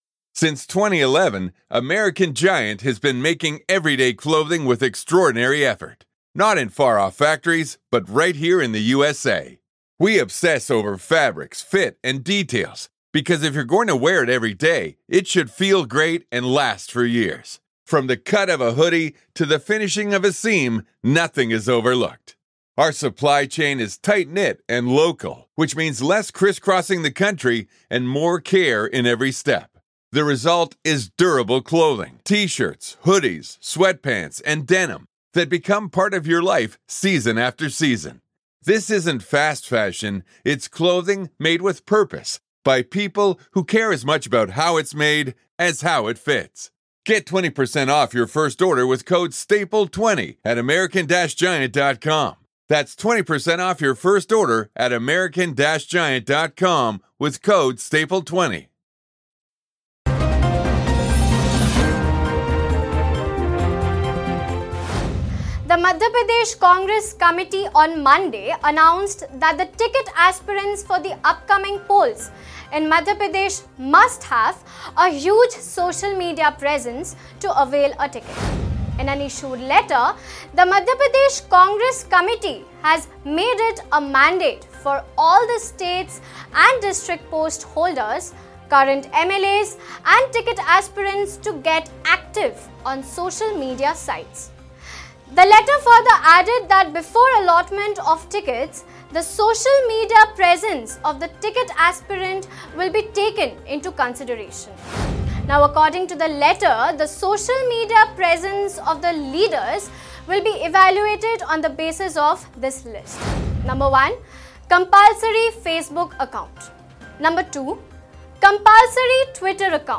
News Report / Congress on BJP's path in MP: Here's what Congress workers need to contest elections